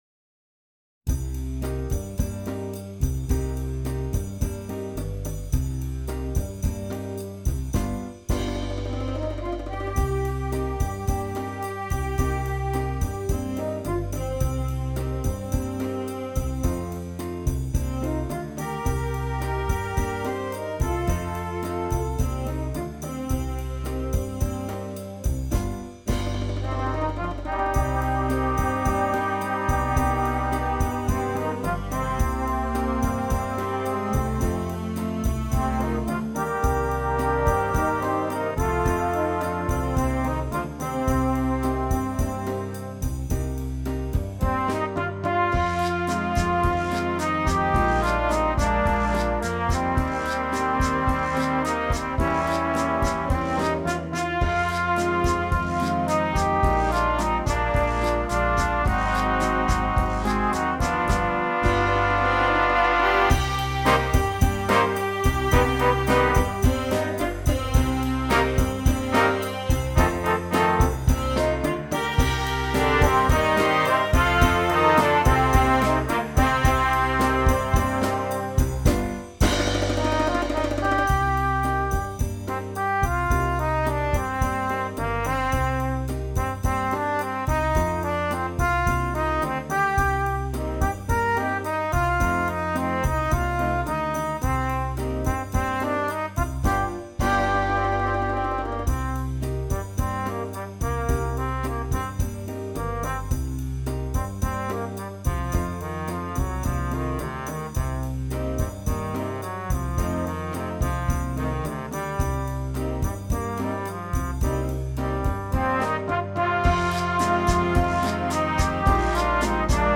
Jazz Band